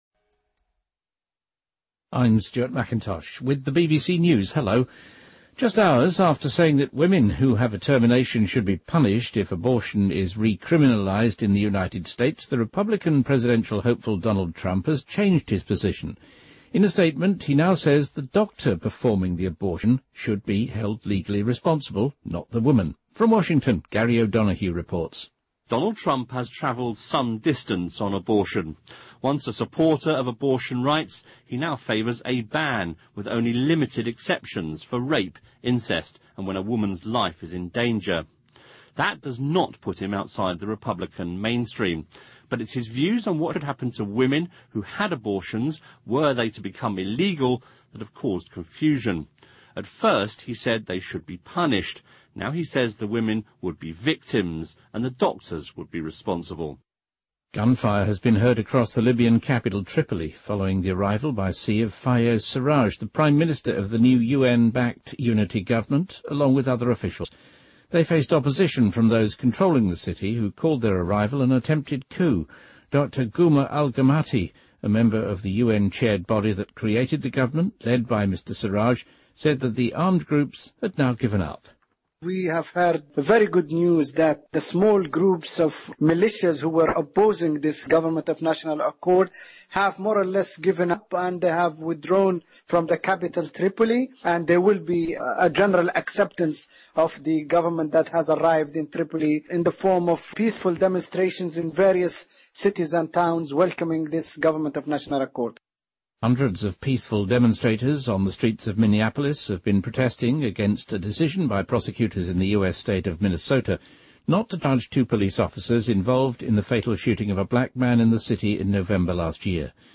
BBC news,南非“刀锋战士”被改判谋杀成立 刑期最低15年
日期:2016-04-02来源:BBC新闻听力 编辑:给力英语BBC频道